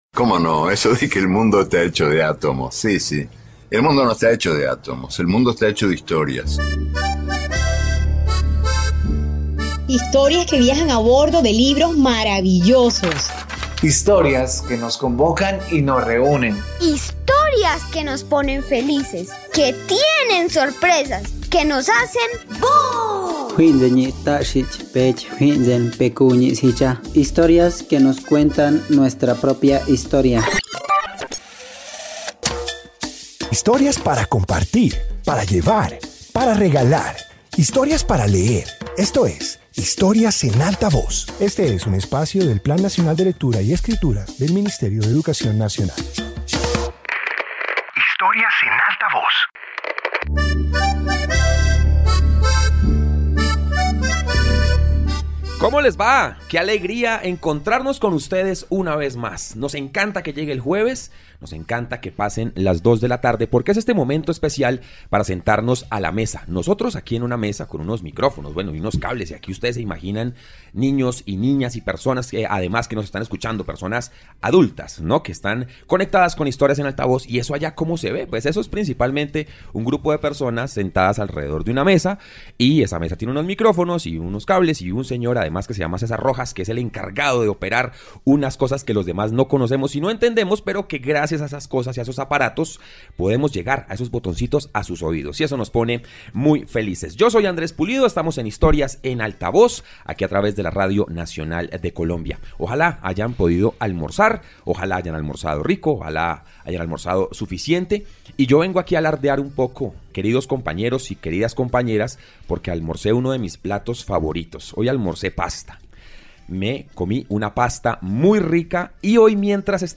Introducción Este episodio de radio presenta historias que muestran el legado cultural europeo. Incluye relatos sobre tradiciones, pensamiento, arte y elementos que han influido en la formación de la cultura occidental.